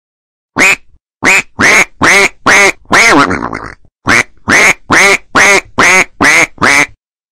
Donald Duck Quack Sound
meme